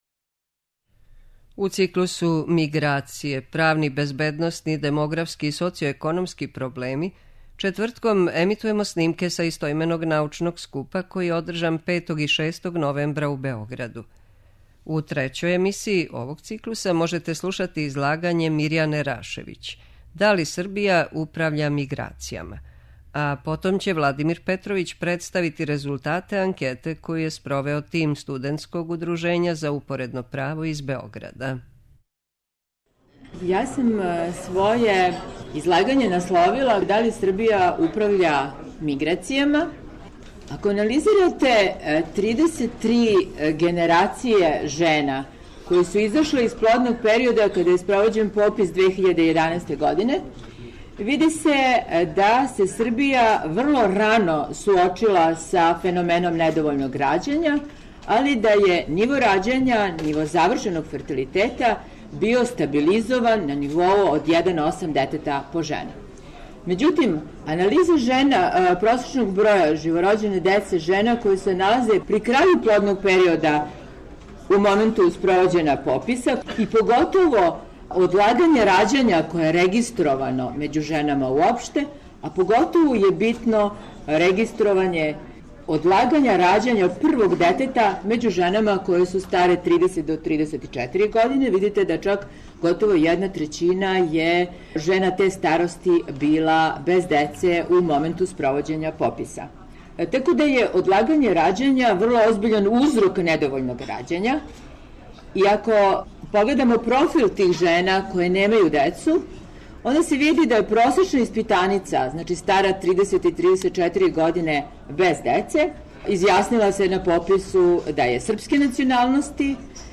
У циклусу МИГРАЦИЈЕ: ПРАВНИ, БЕЗБЕДНОСНИ, ДЕМОГРАФСКИ И СОЦИО-ЕКОНОМСКИ ПРОБЛЕМИ четвртком емитујемо снимке са истоименог научног скупа који је одржан 5. и 6. новембра у Хотелу Палас у Београду.